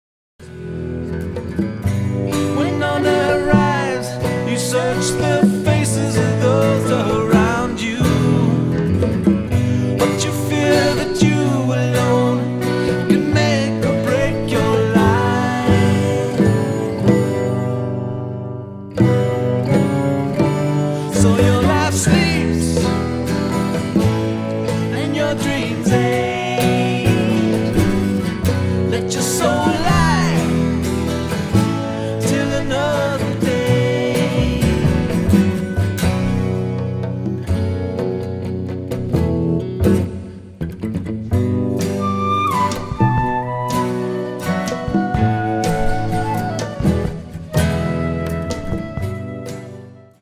acoustic original